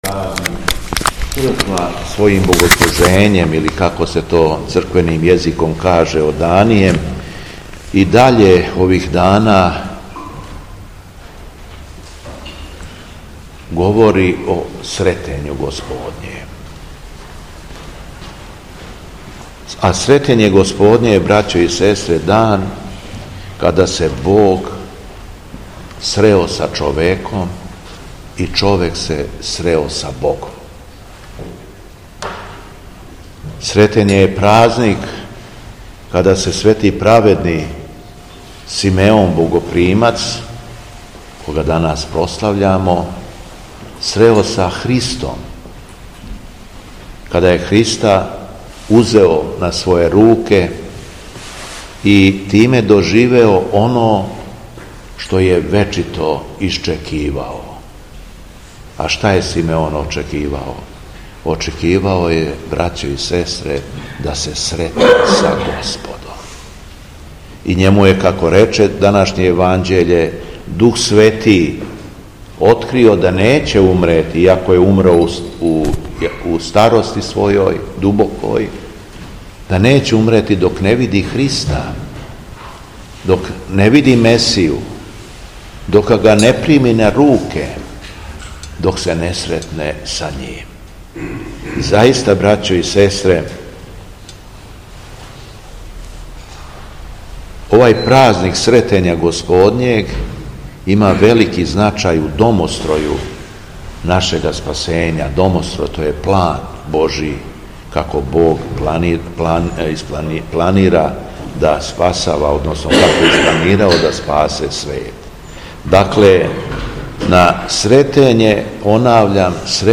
ВЛАДИКА ЈОВАН СЛУЖИО У СТАРОЈ ЦРКВИ У КРАГУЈЕВЦУ - Епархија Шумадијска
Беседа Његовог Преосвештенства Епископа шумадијског г. Јована
Беседећи верном народу, Владика је рекао: